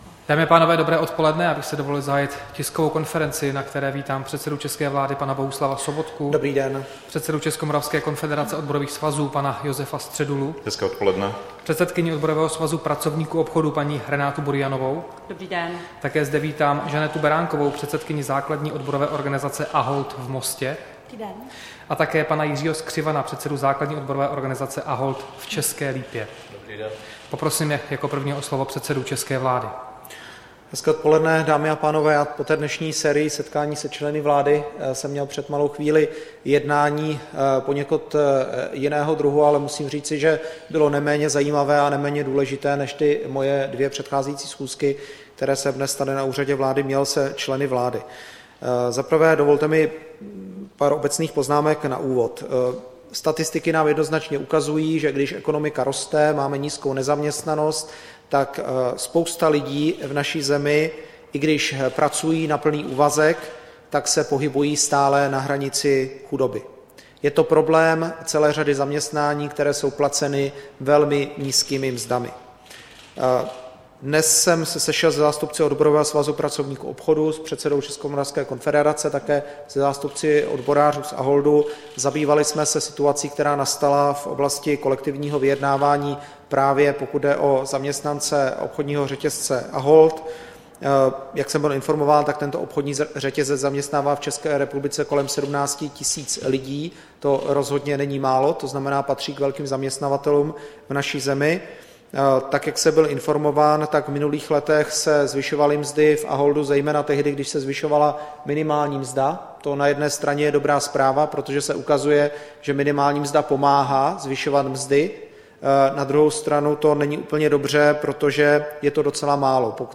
Tisková konference po jednání se zástupci odborů ve společnosti Ahold v ČR, 22. listopadu 2016